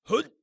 ZS蓄力6.wav
人声采集素材/男3战士型/ZS蓄力6.wav